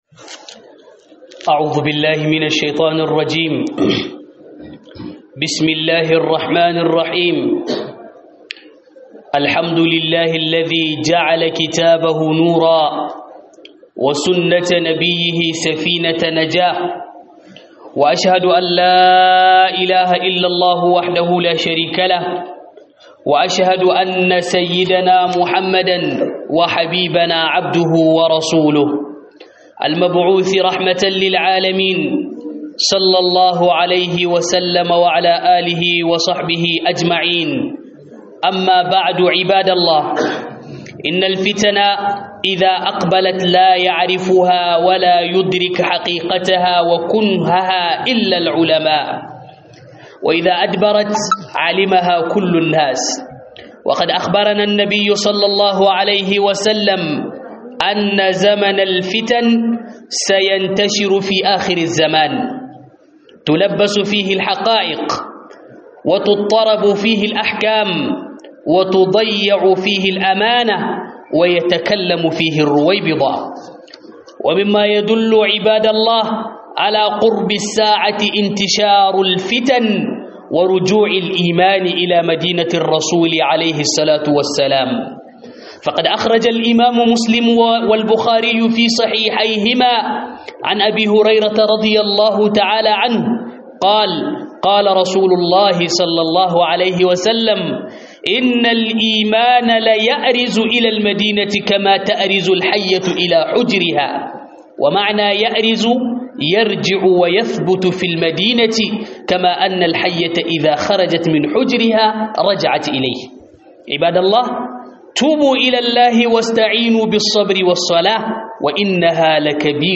Huɗubar juma'a Kariya daga fitina